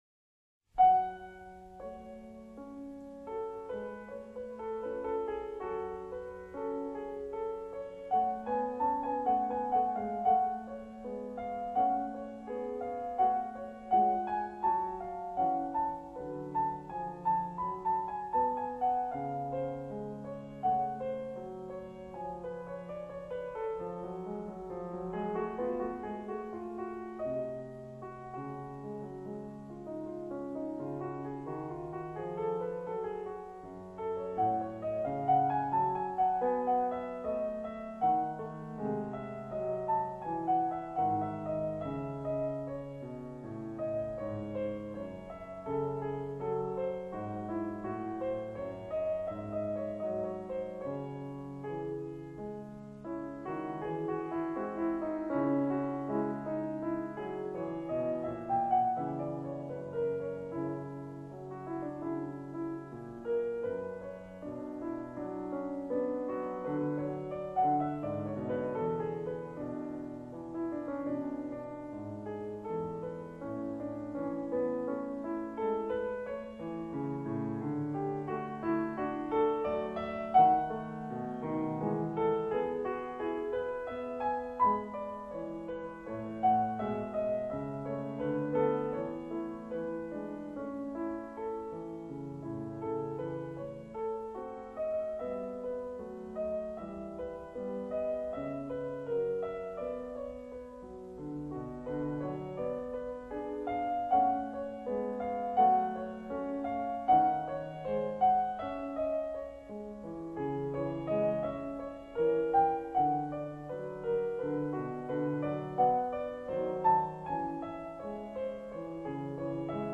01 Prelude&Fugue No.14  In F sharp minor, BWV883